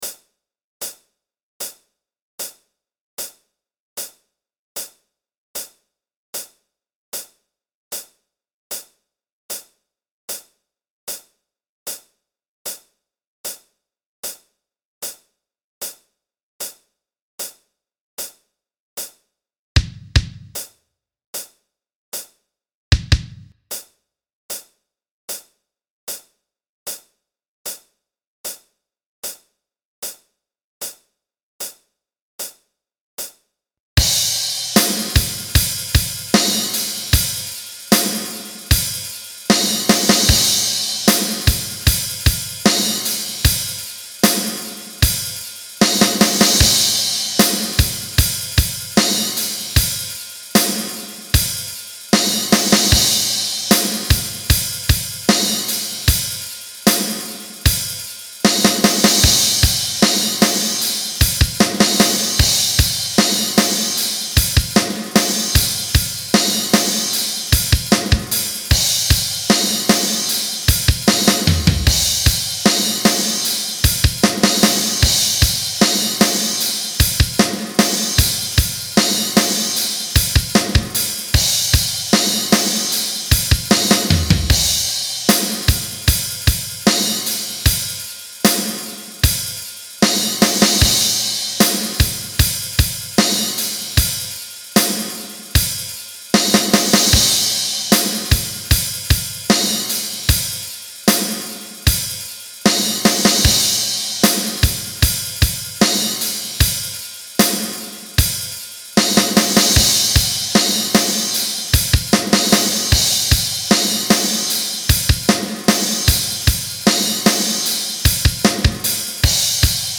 Drums only - create what you want